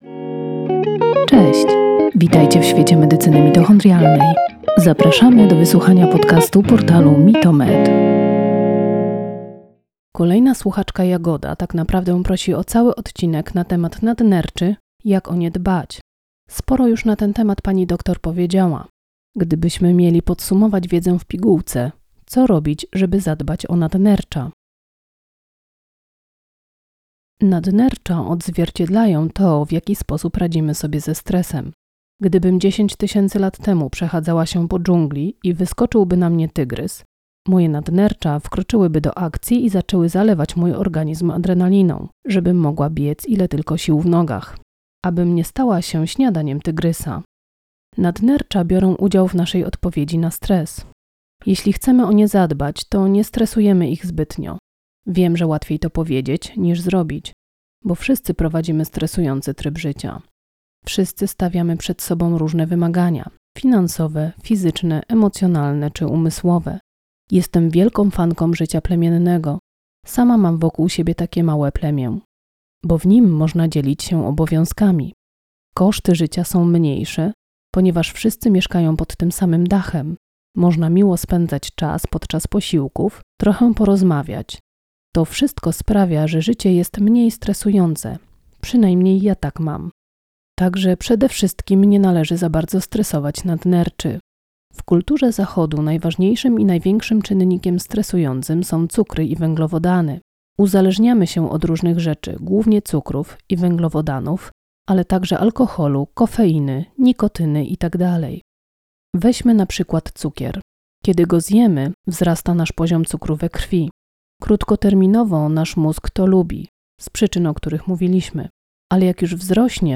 Przedstawimy wywiad